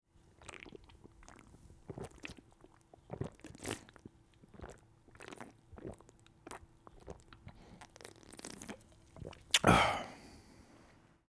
Thirsty Man Drinking Water